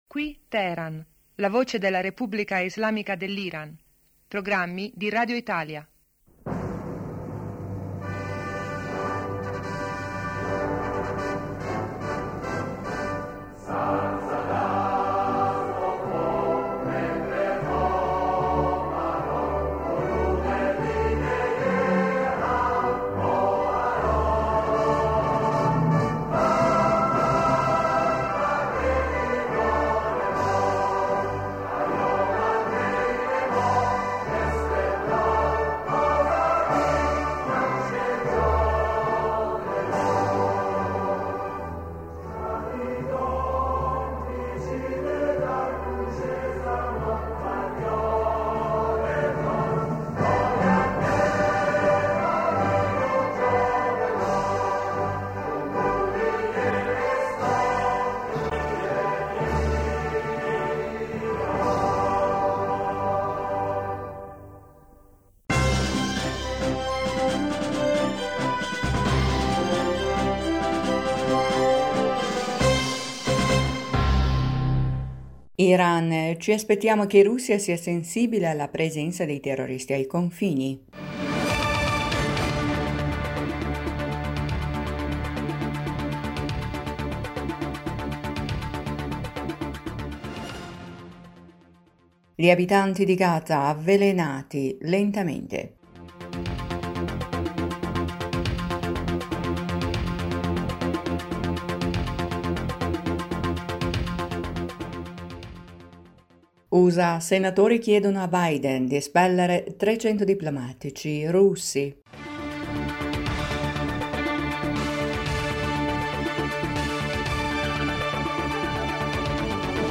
Ecco i titoli importante del nostro radiogiornale:1-Iran: ci aspettiamo che Russia sia sensibile alla presenza dei terroristi ai confini,2-Il presidente ci...